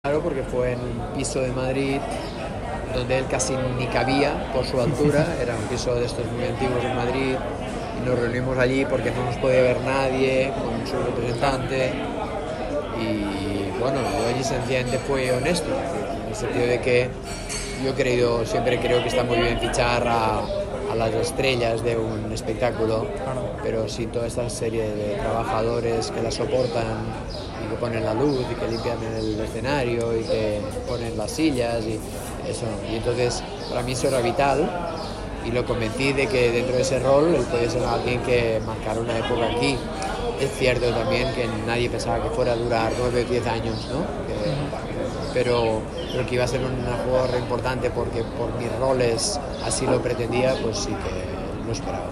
El catalán recuerda aquella reunión en Radio Marca Málaga
Una charla «honesta» en Madrid que recuerda el técnico en Radio Marca Málaga.